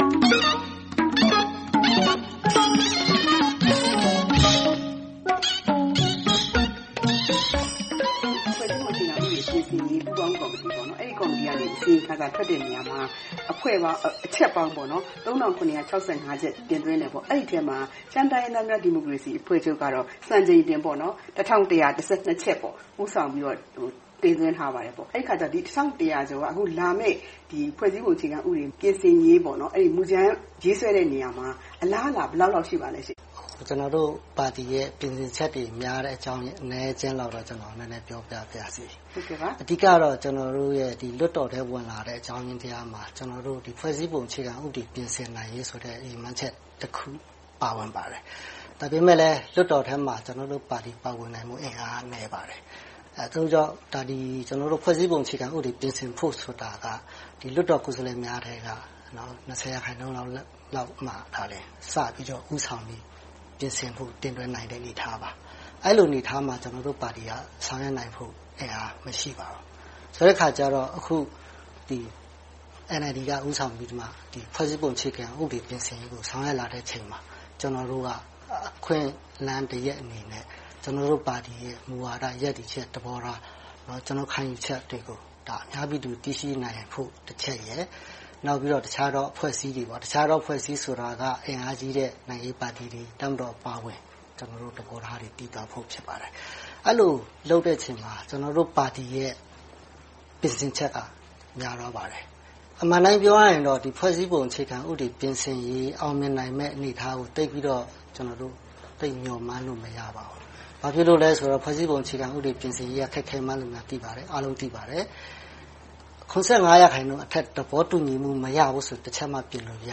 သြဂုတ် ၂၅၊ ၂၀၁၉ - ရှမ်းတိုင်းရင်းသားများ ဒီမိုကရေစီအဖွဲ့ချုပ်၊ မိုင်းရယ်ပြည်သူ့လွှတ်တော်ကိုယ်စားလှယ် စိုင်းသီဟကျော်က ပြောပါတယ်။